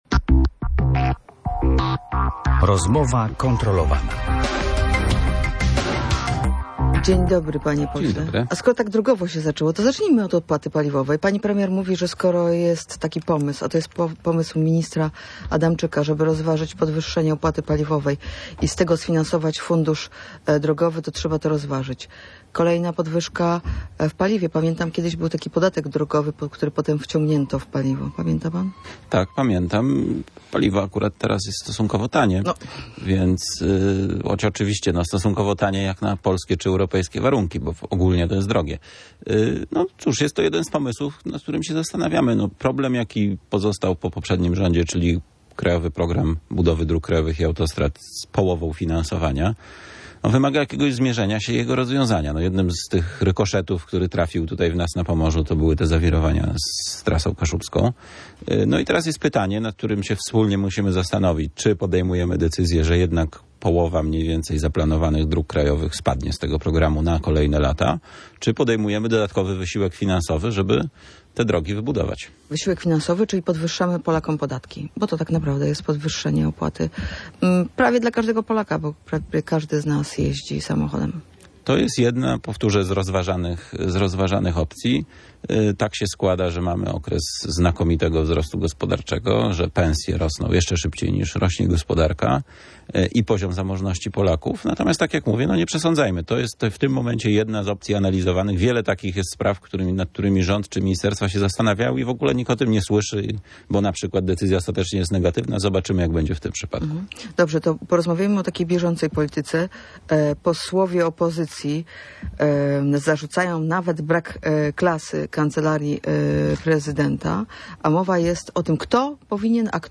Gościem Rozmowy kontrolowanej był poseł Prawa i Sprawiedliwości Marcin Horała. Wywiad